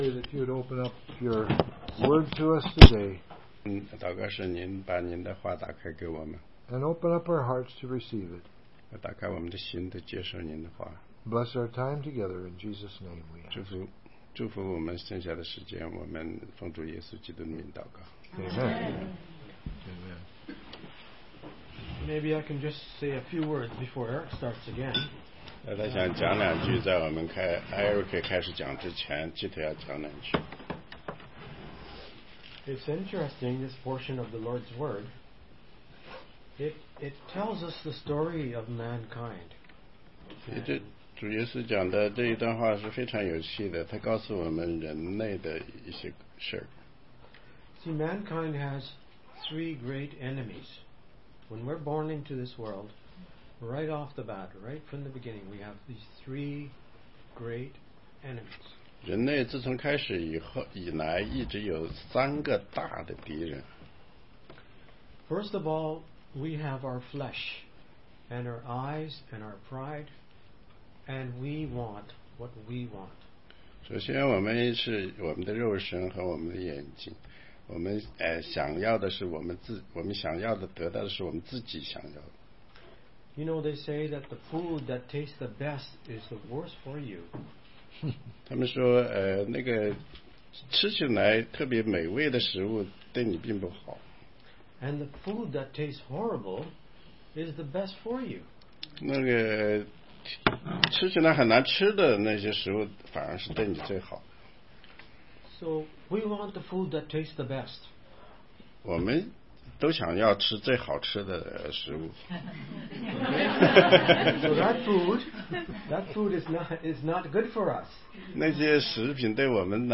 16街讲道录音 - 约翰福音